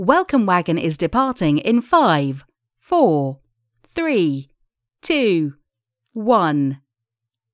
countdown.wav